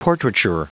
Prononciation du mot portraiture en anglais (fichier audio)